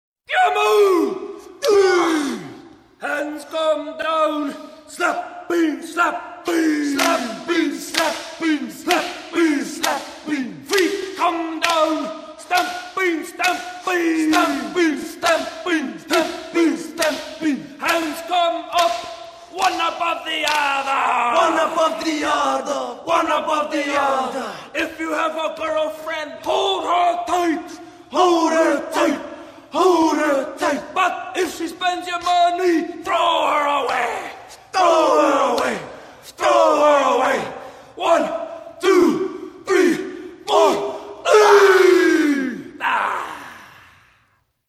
An example of a more light-hearted problem caused during the introduction of a specific Maori haka concerns the lyrics of the chant Hands Come Down (